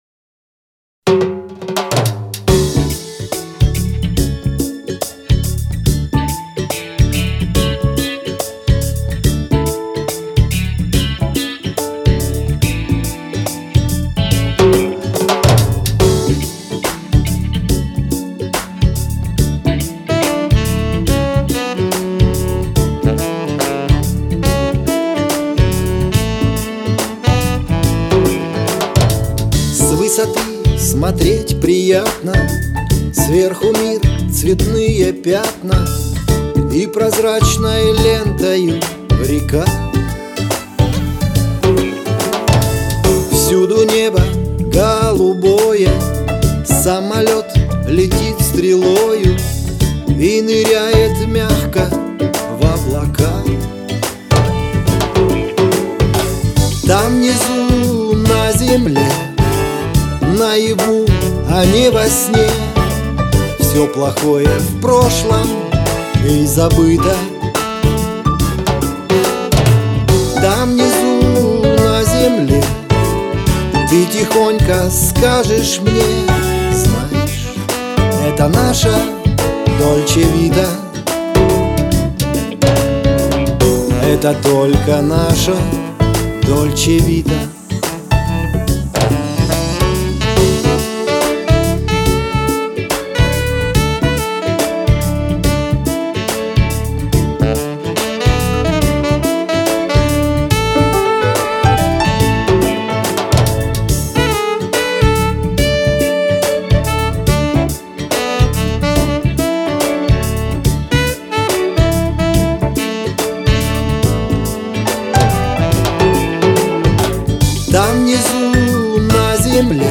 Инструментал уже приобретает очертания....
Сам по себе, и 0 эмоций. Сакс отправьте хоть в реверок поглыбже, всё тесновато и булькает;;;